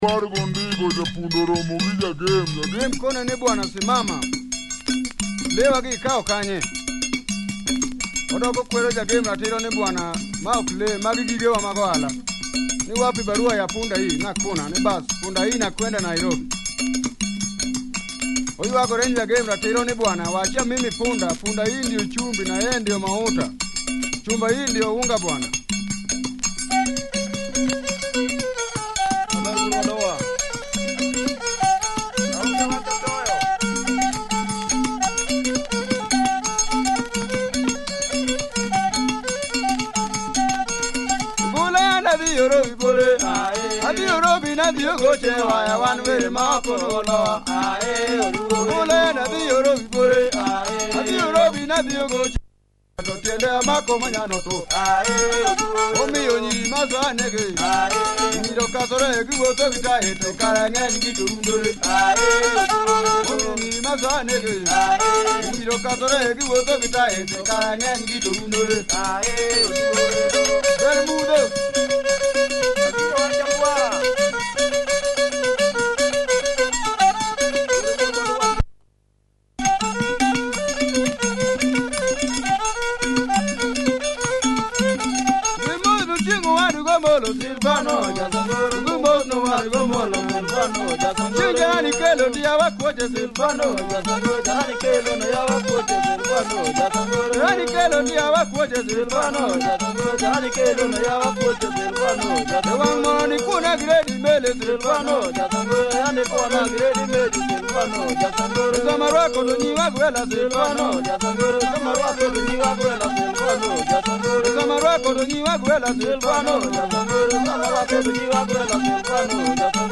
Traditional Luo percussion and string with vocals, heavyy!!